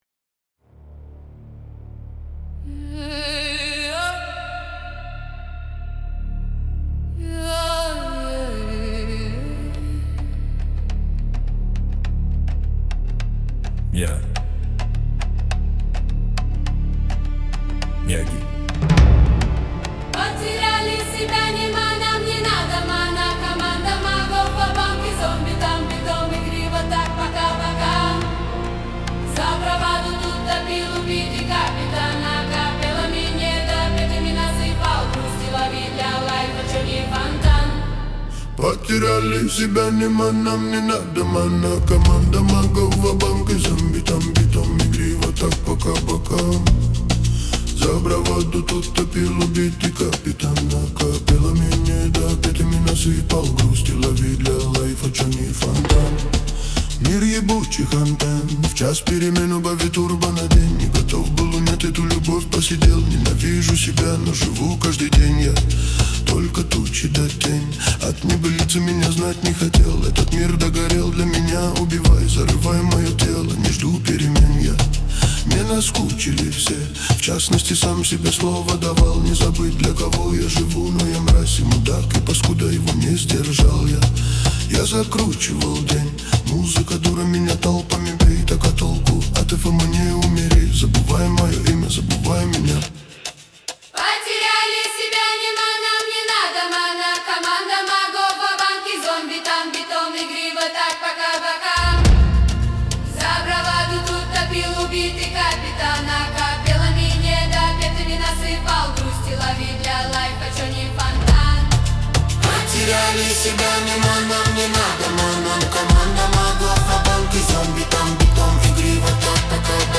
Afro Soul кавер